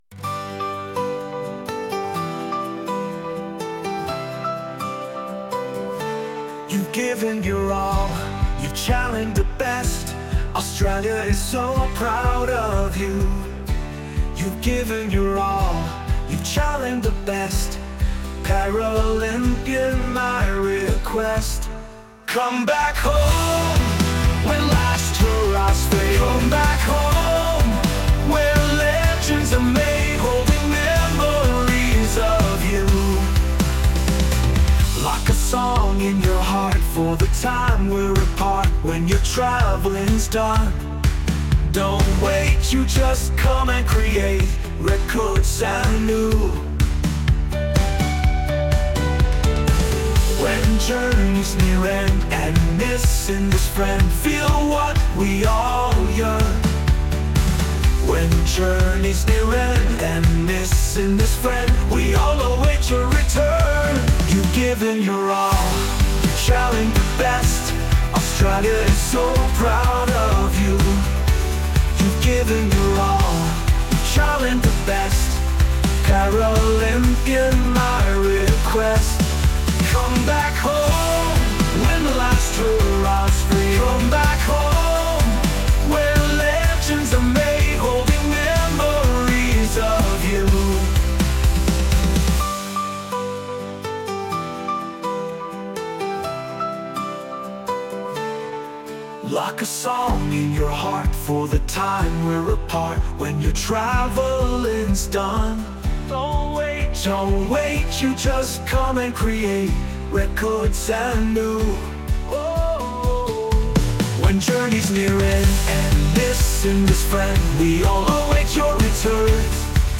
e.g. Paralympians  Australia  -male-01
male-02